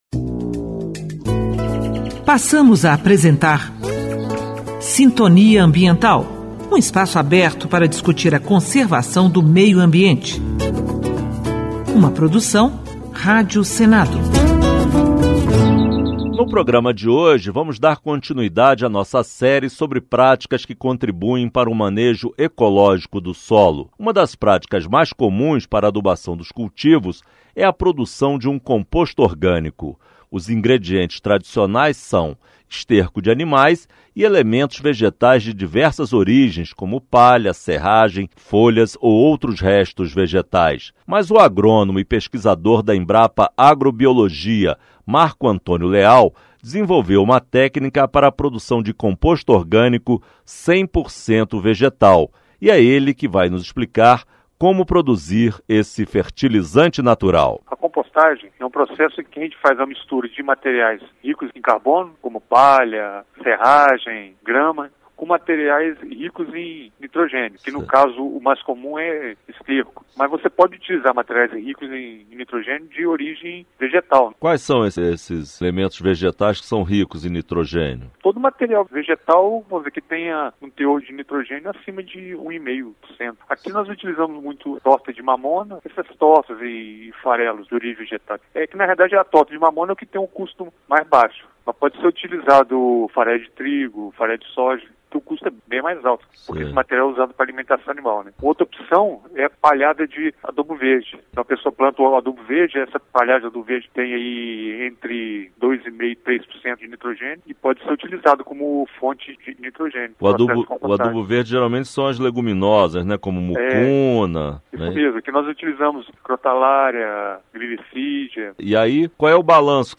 Programa veiculado na Rádio Senado entre junho de 2010 e junho de 2014.